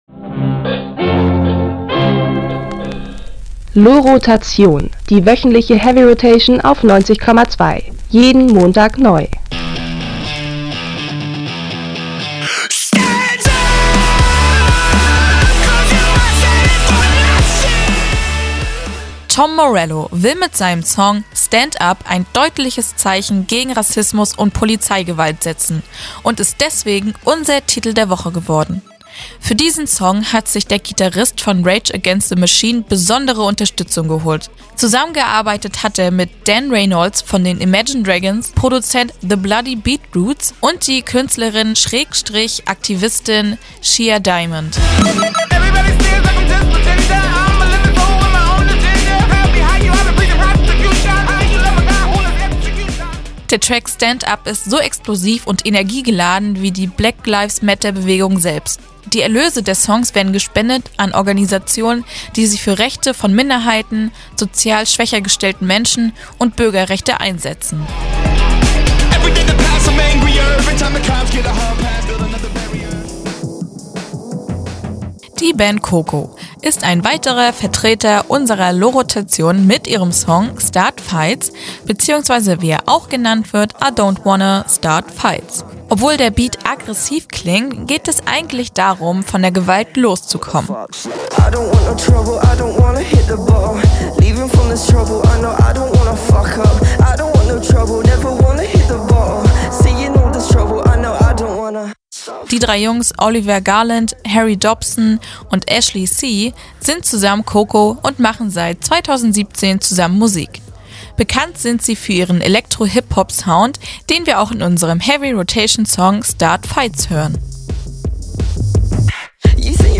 Ein Gitarrist wird abermals zum "wütenden Propheten"
kommt kraftvoll daher
slamming track